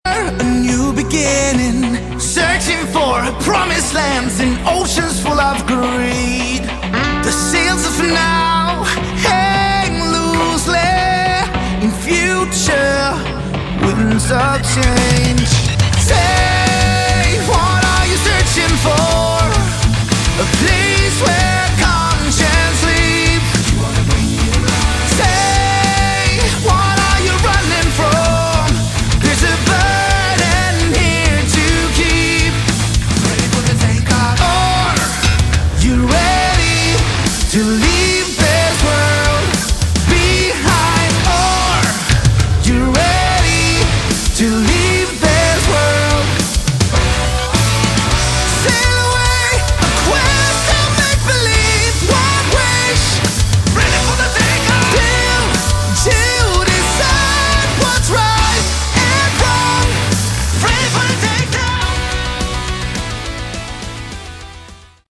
Category: Hard Rock
Vocals
Guitars, Backing Vocals
Bass, Backing Vocals
Drums, Percussion